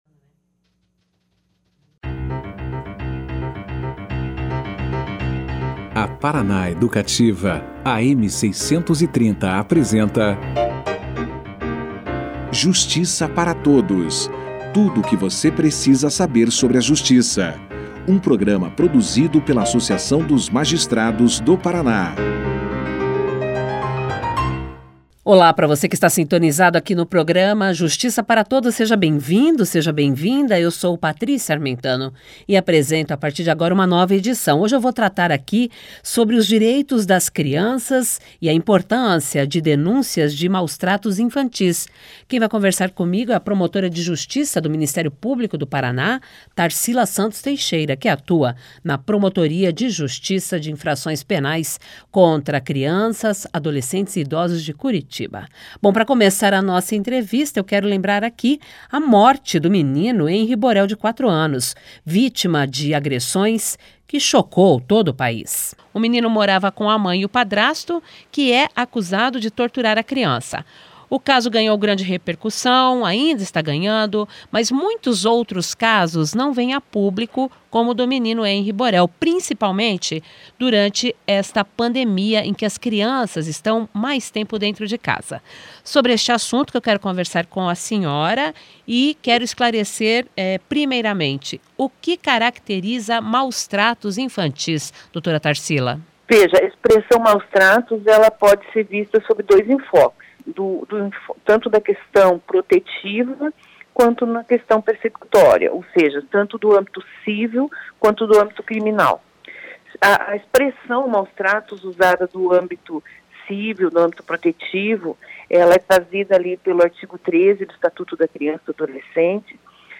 E, também, o que fazer em caso de alerta. Confira aqui a entrevista na integra.